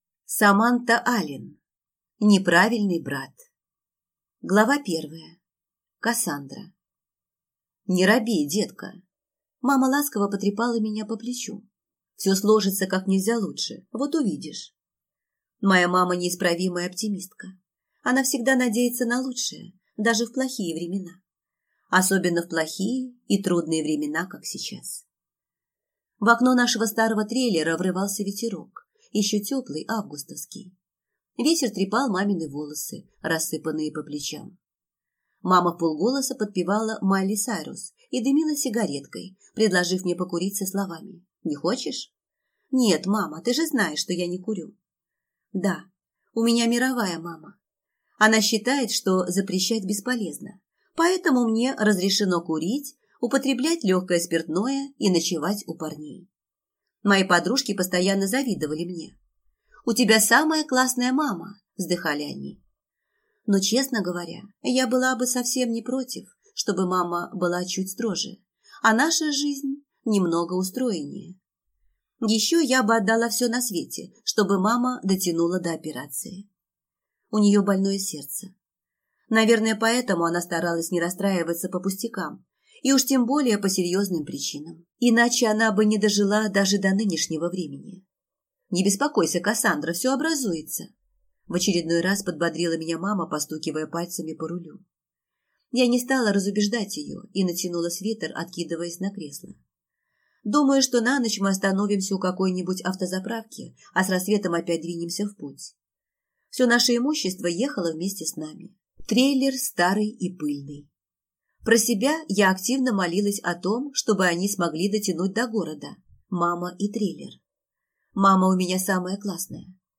Аудиокнига Неправильный брат | Библиотека аудиокниг